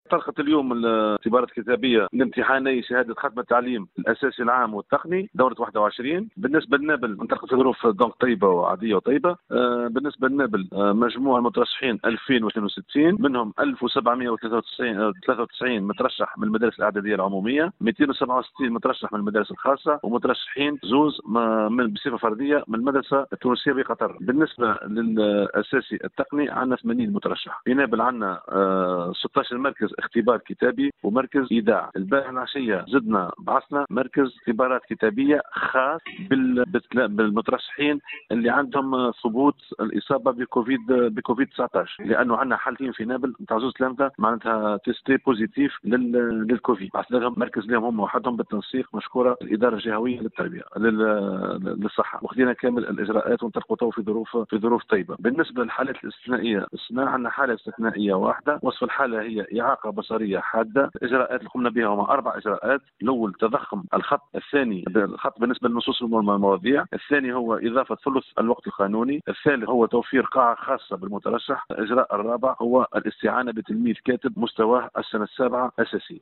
أعلن المندوب الجهوي للتربية بنابل، زكرياء داسي، عن تسجيل حالتي إصابة بفيروس "كورونا" في صفوف مترشحين لاجتياز اختبارات شهادة ختم التعليم الأساسي العام والتقني.